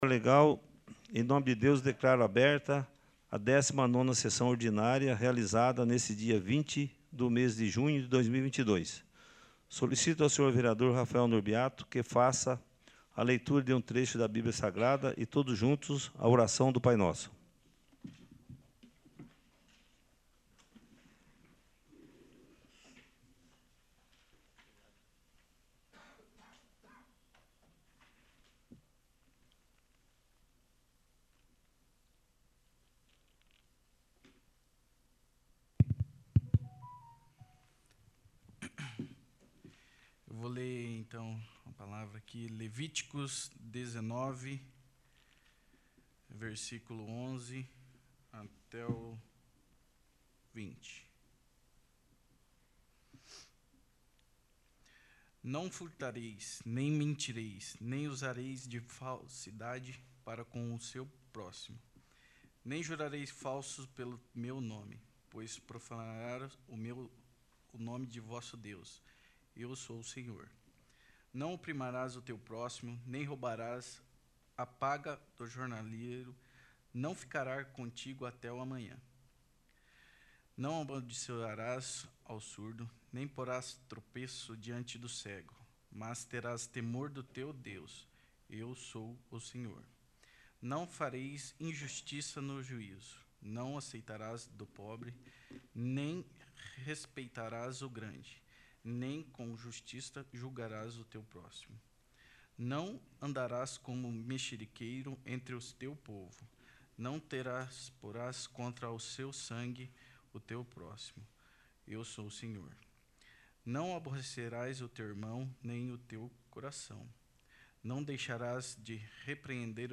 19º. Sessão Ordinária